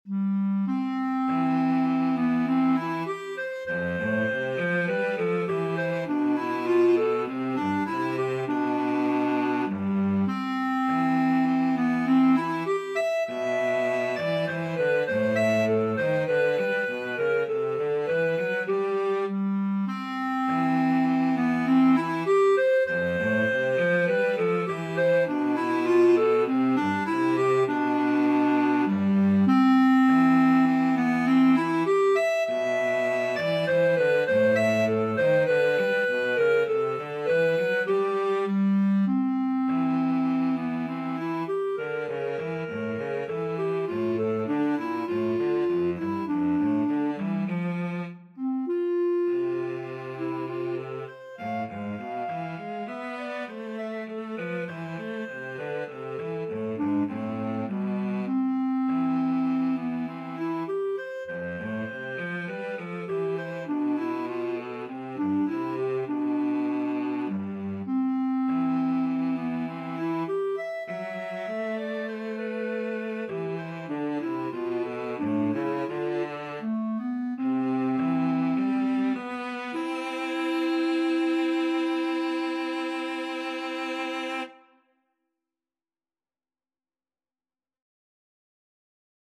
4/4 (View more 4/4 Music)
Classical (View more Classical Clarinet-Cello Duet Music)